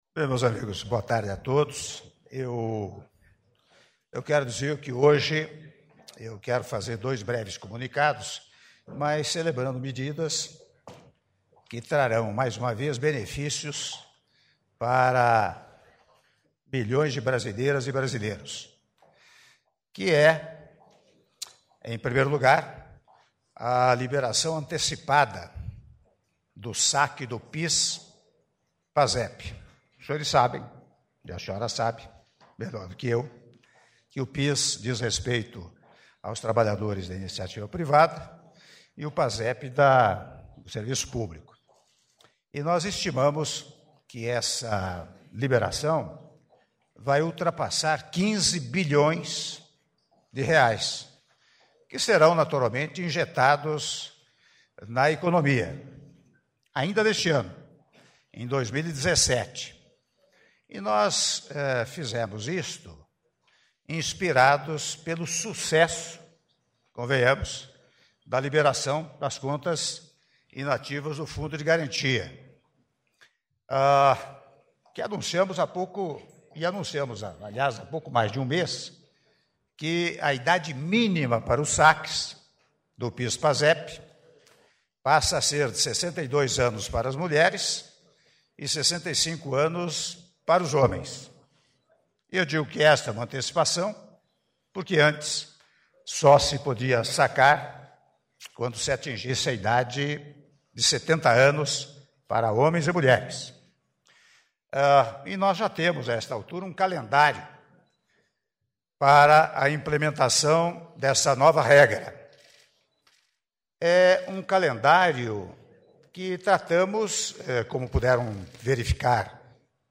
Áudio das palavras Presidente da República, Michel Temer, alusivas à antecipação do pagamento de recursos do PIS-PASEP (08min21s)